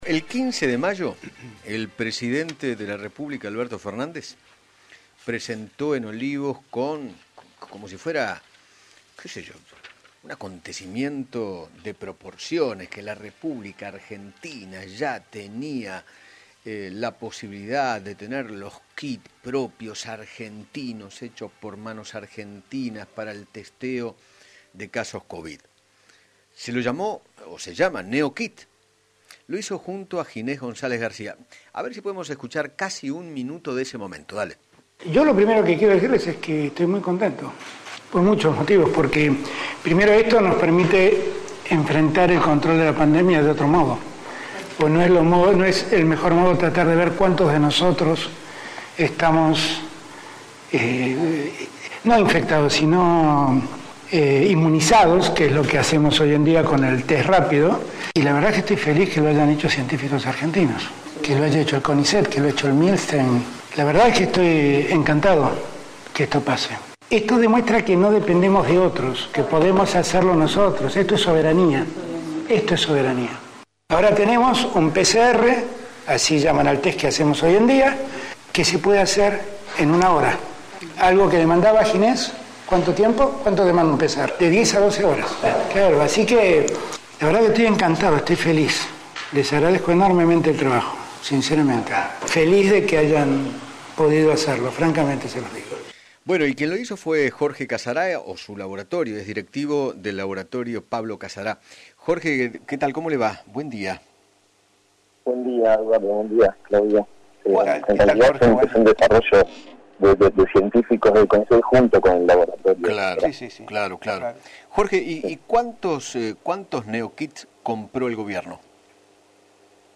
dialogó con Eduardo Feinmann